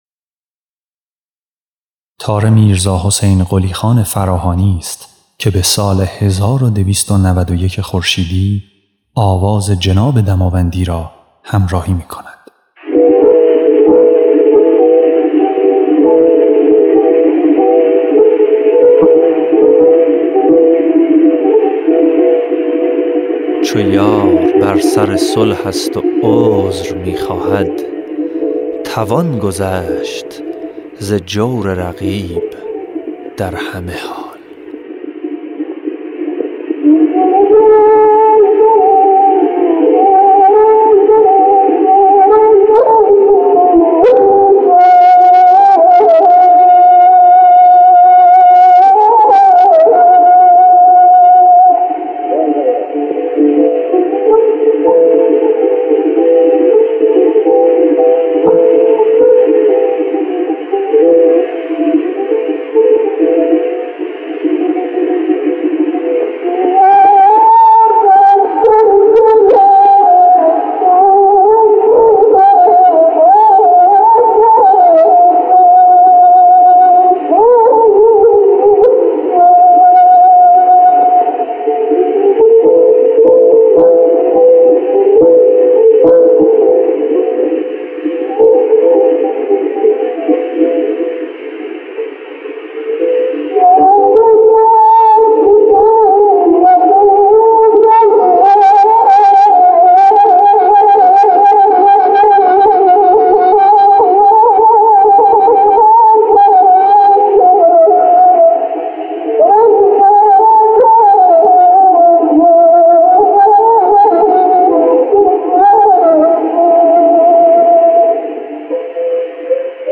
خواننده
نوازنده تار